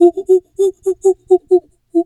Animal_Impersonations
monkey_2_chatter_10.wav